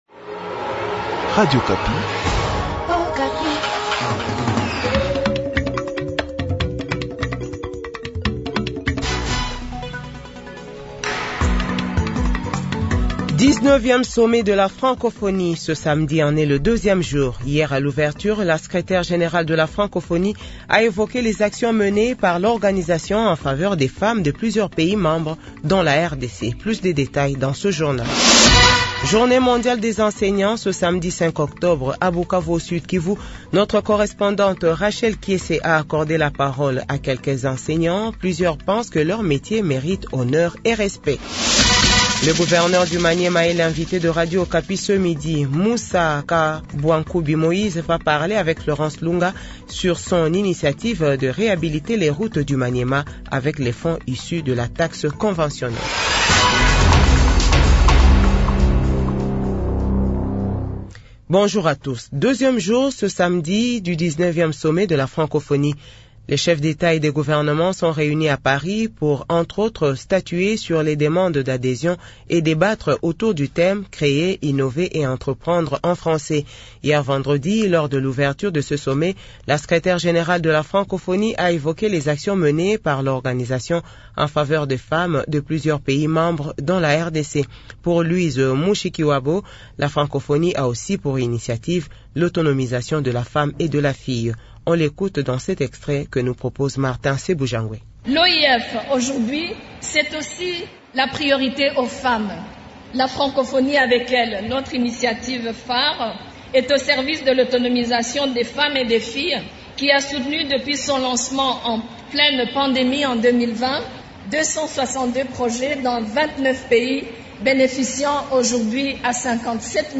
JOURNAL FRANÇAIS DE 12H00